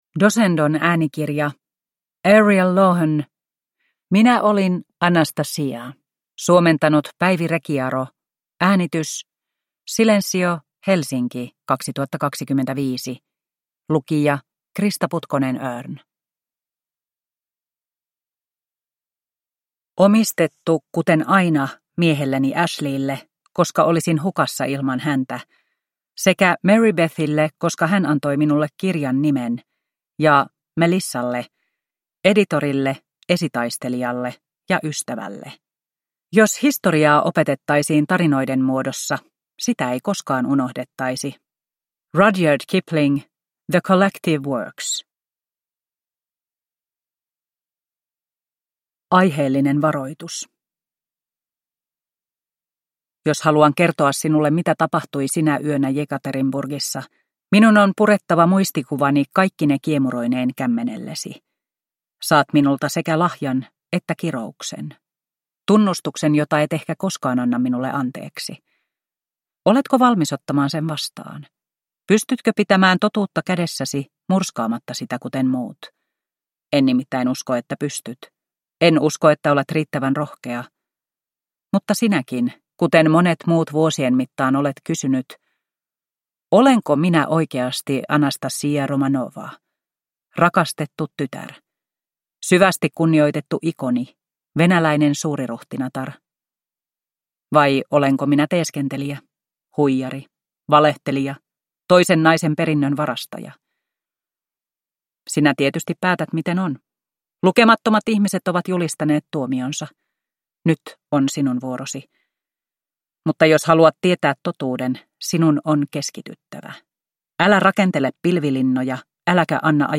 Minä olin Anastasia (ljudbok) av Ariel Lawhon